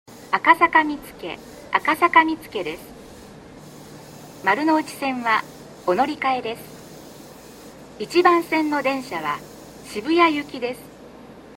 B線ホームで収録すると、真上にA線の線路があるのでよくガタンゴトンと被ります・・。
接近放送